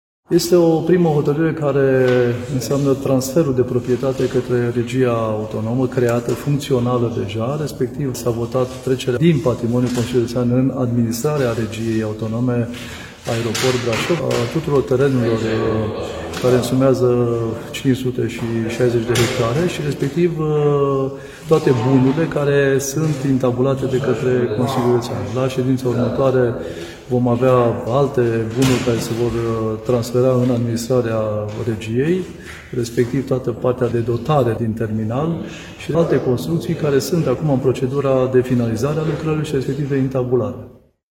Vicepreședintele CJ Brașov, Șerban Todorică: